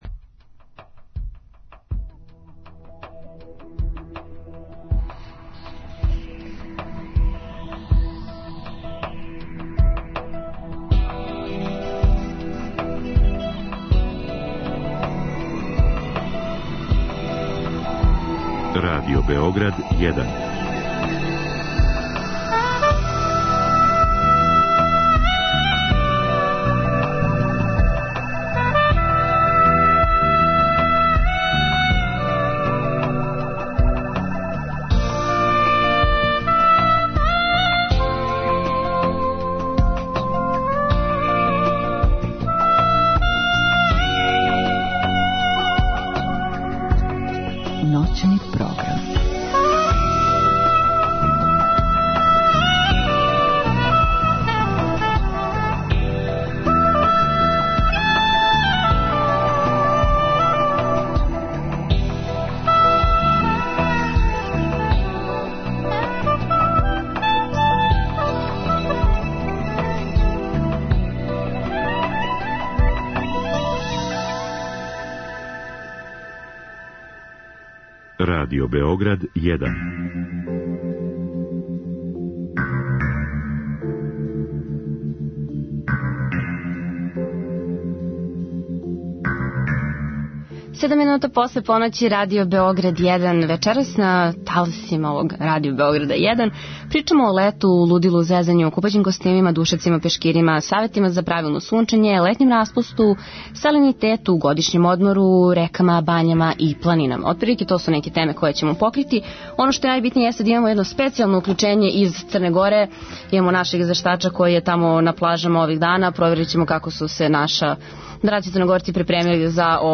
Гости ће бити пажљиво селектовани комичари са летњег StandUp Феста који се одржава на Калемегдану.